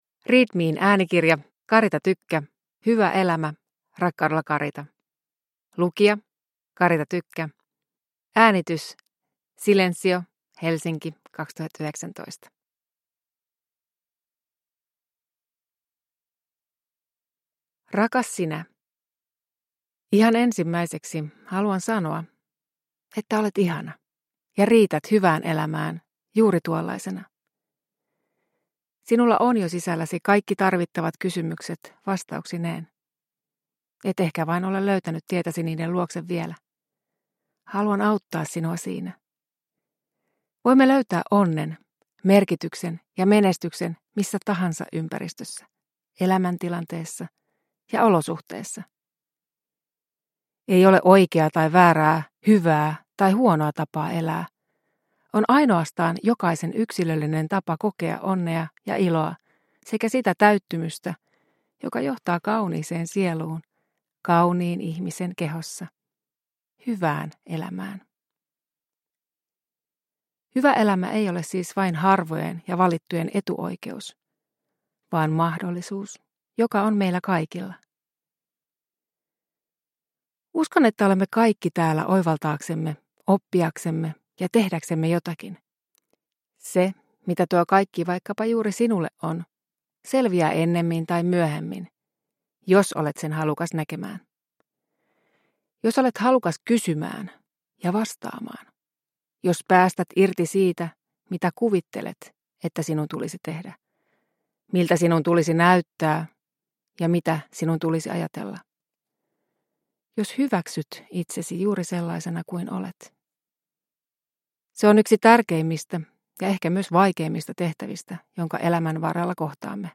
Hyvä elämä – Ljudbok